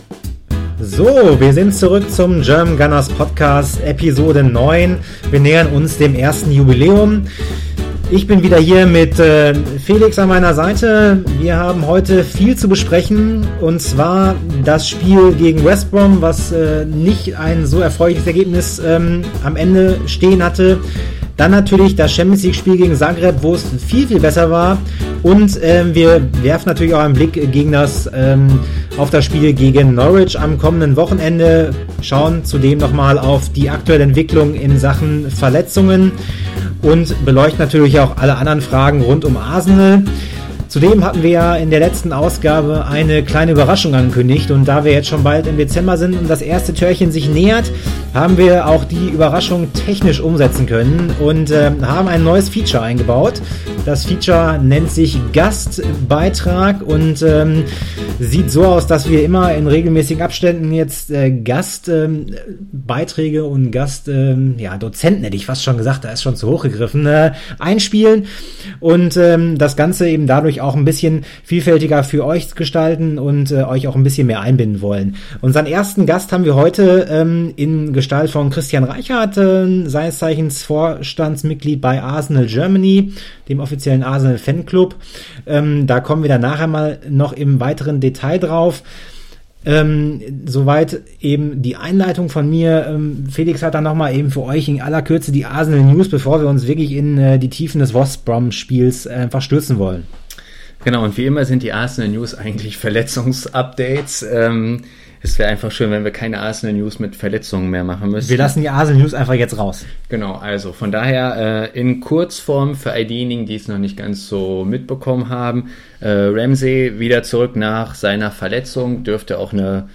Wie angekündigt mit einem neuen Feature in Gestalt eines Gastredners.